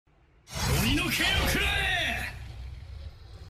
Genji's Ultimate - Overwatch Sound Effect MP3 Download Free - Quick Sounds